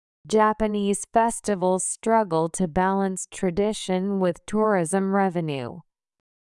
速度がスローなので、中上級者なら（ケアレスミスをしなければ）満点を狙えます。